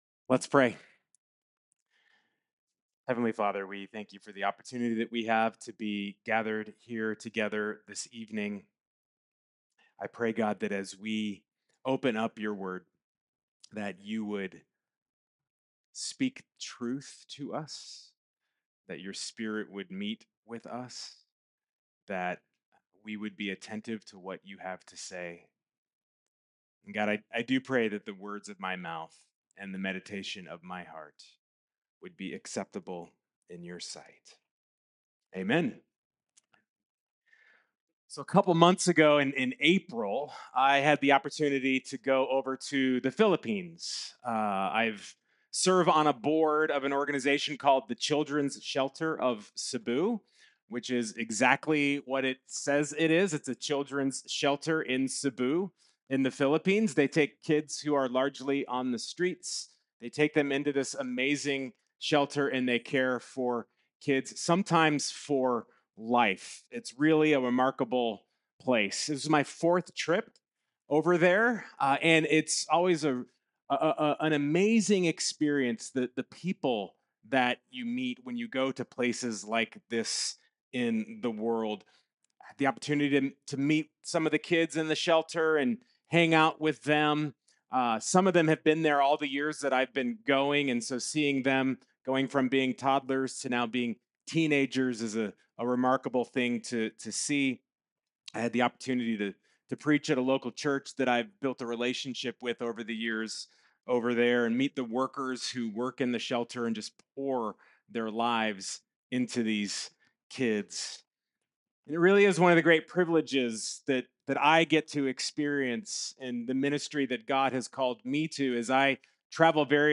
Weekly messages from Emmanuel Covenant Church, Twin Cities, MN.